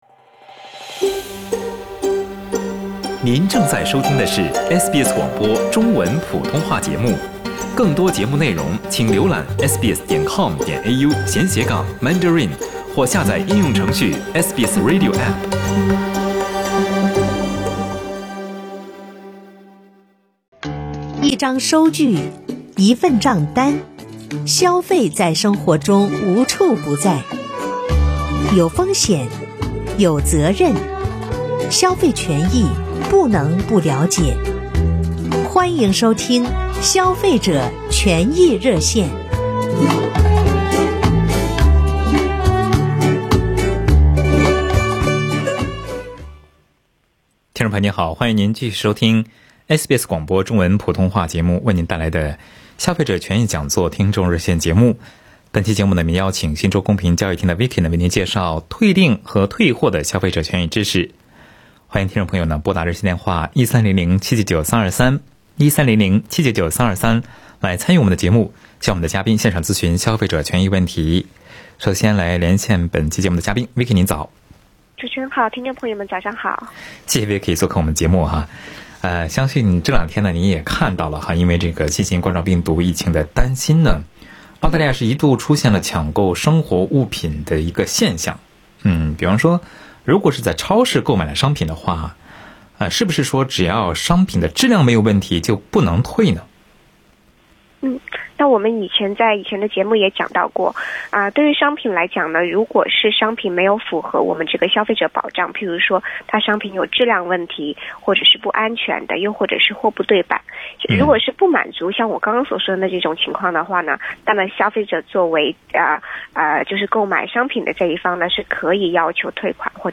一位听众在《消费者权益讲座》听众热线中表示，自己的退休金Super账户中的人寿保险在在十多年间翻了二十多倍，退休金余额将近被扣完。